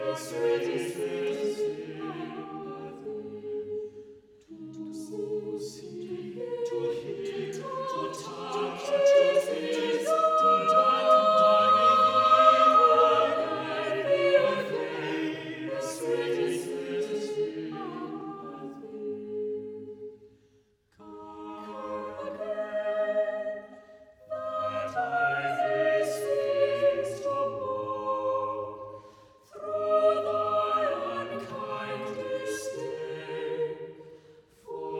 Classical Choral
Жанр: Классика